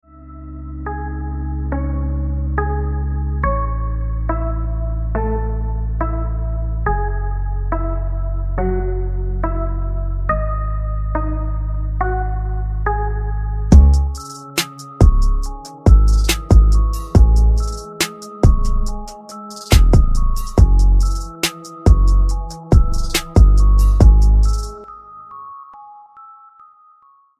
English Ringtones